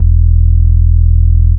Subarctic Bass 65-06.wav